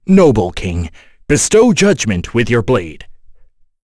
Dakaris-Vox_Skill1_b.wav